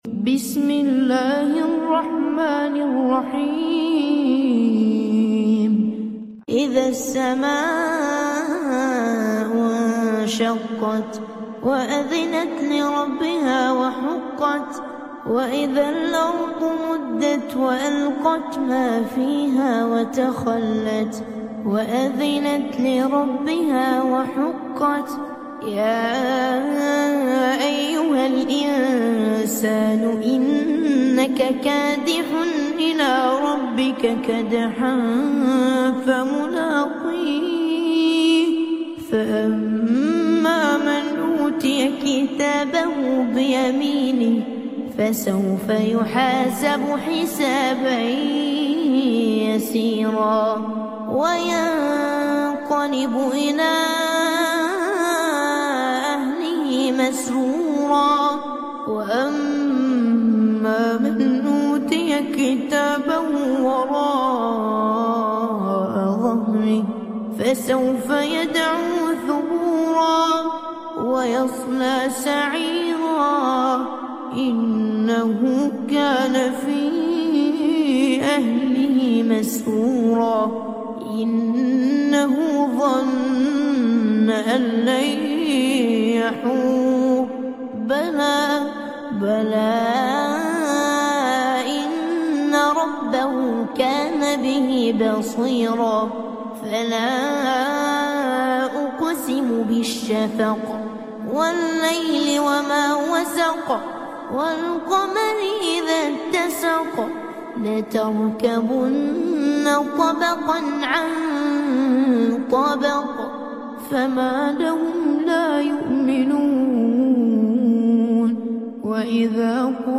Calming Quran Recitation With Beautiful Voice